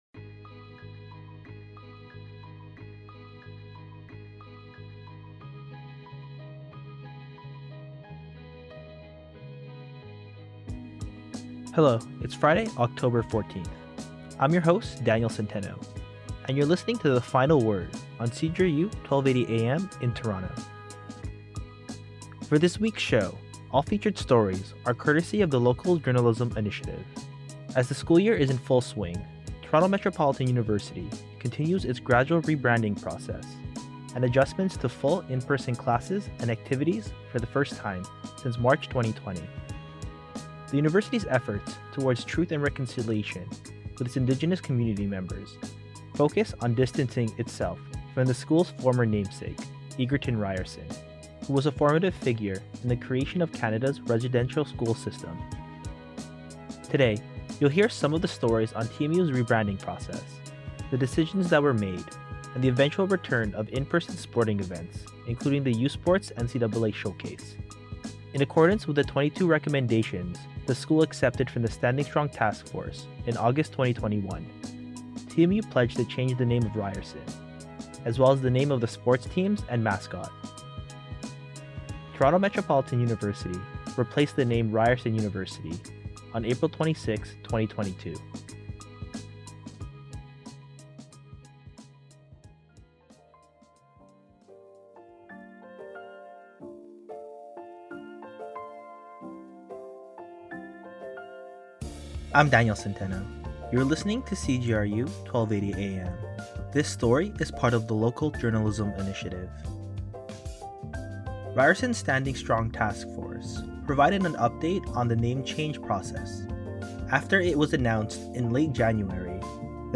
CJRU The Final Word is CJRU’s weekly half-hour news program, combining long-form journalism, interviews, breaking news and more from a TMU-centred perspective.